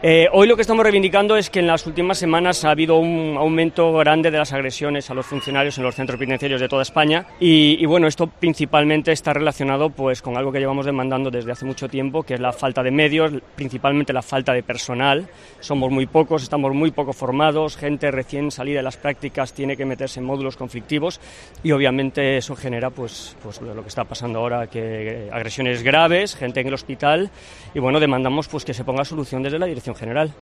Corte de voz sobre agresiones en las prisiones